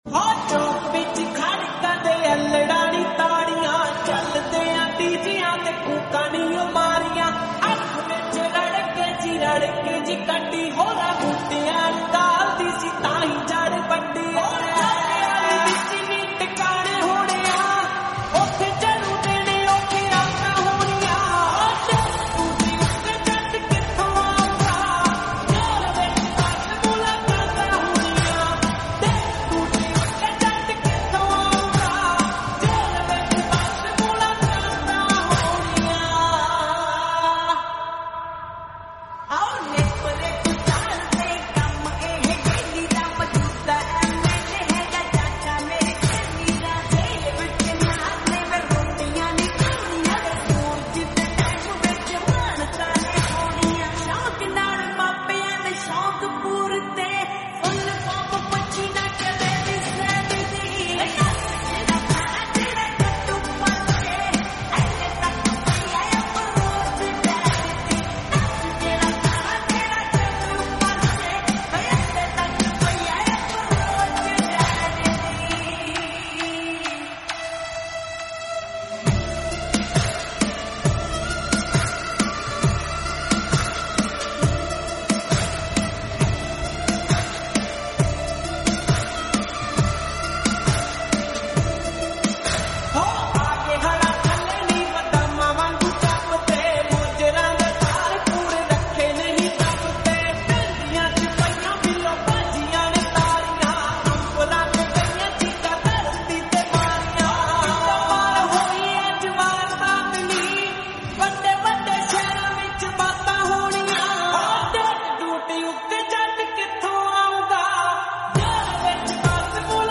Punjabi song